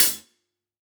Index of /musicradar/Kit 1 - Acoustic close
CYCdh_K1close_ClHat-09.wav